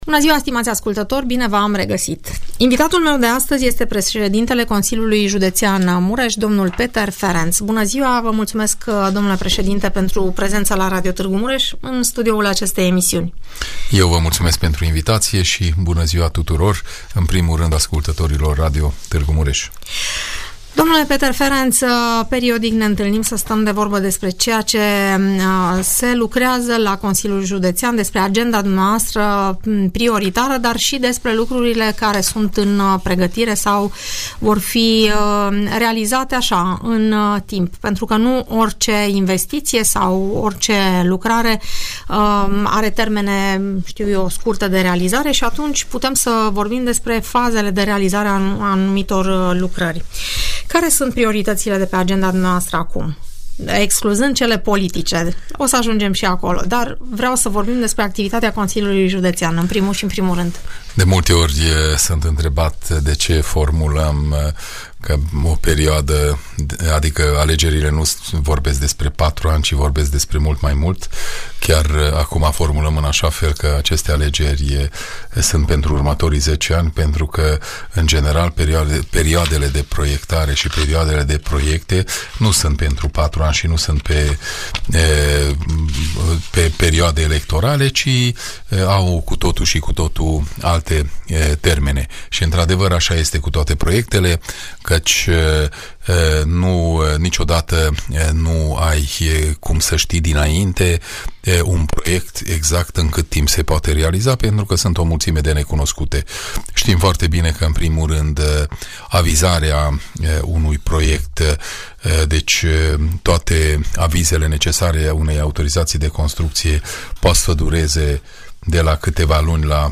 Peter Ferenc, președintele Consiliului Județean Mureș, explică în emisiunea „Părerea ta”, care sunt proiectele în curs de finalizare, dar și cele care urmează să fie demarate la nivel de județ.